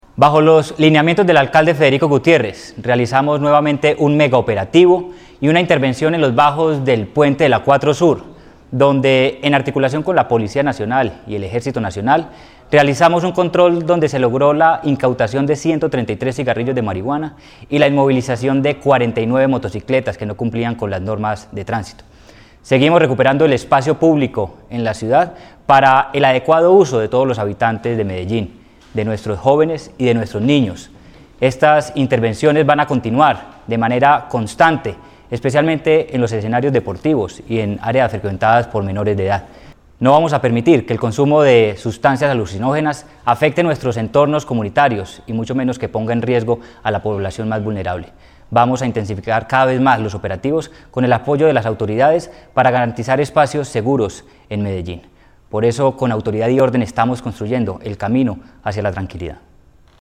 Palabras de Manuel Villa Mejía, secretario de Seguridad y Convivencia